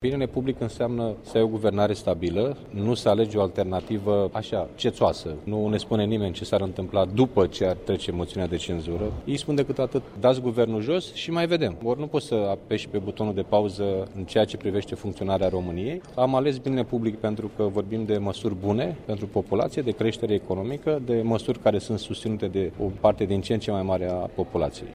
Președintele interimar al PSD, Liviu Dragnea, a declarat, dupã o reuniune a coaliției, cã parlamentarii puterii vor asigura, mâine, cvorumul de ședințã, dar nu vor participa la vot :
dragnea_motiune.mp3